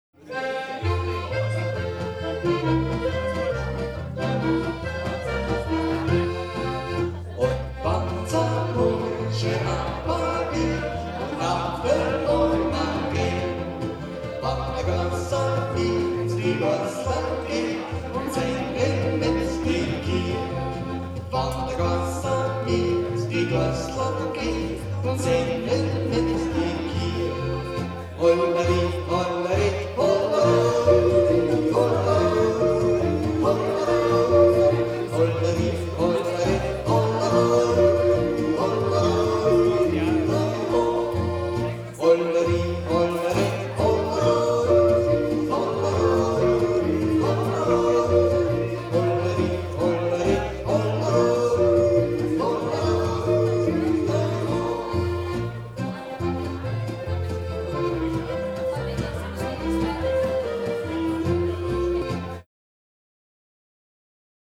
Jodler, Jodler-Lied, Gstanzl und Tanz - Geradtaktig
Yodel, Yodel-Song – duple metre (2/4); Lower Austrian and Styrian Wechsel-region; social structure; local dialect
Folk & traditional music